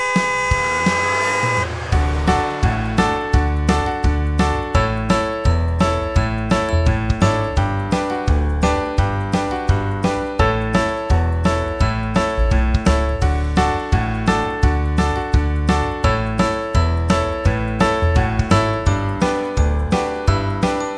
utwór w wersji instrumentalnej